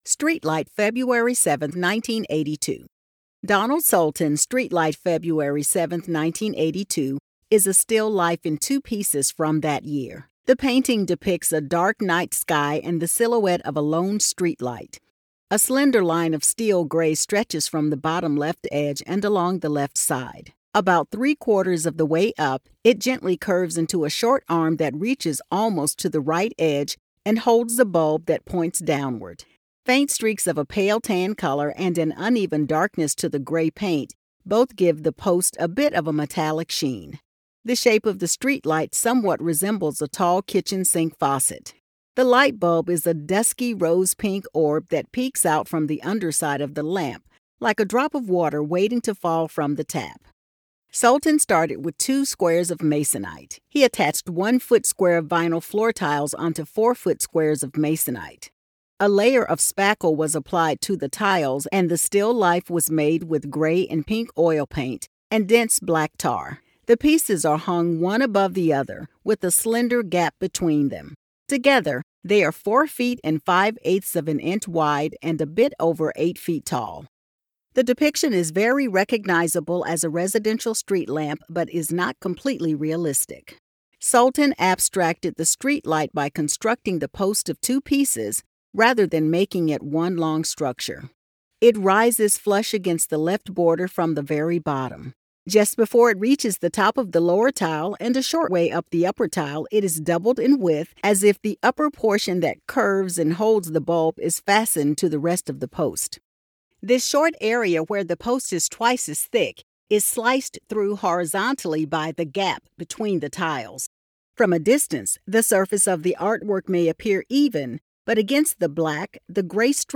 Audio Description (02:55)